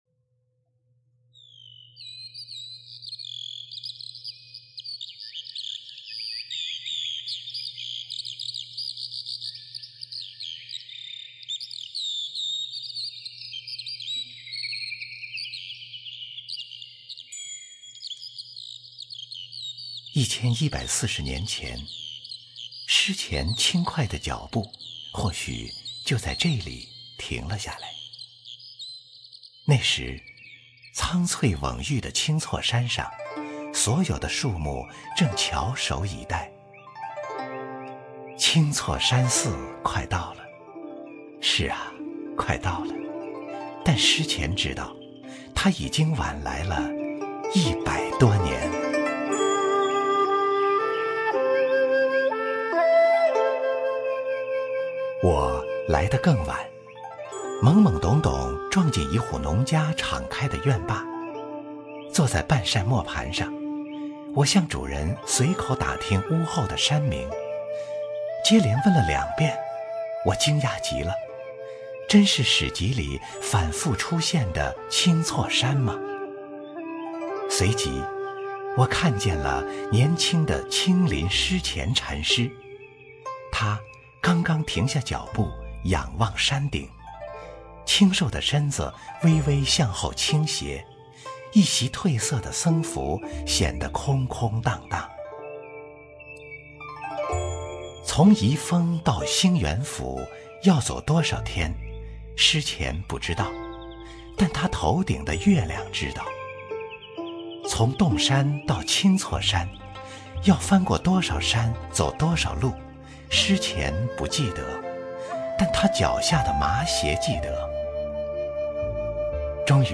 经典朗诵欣赏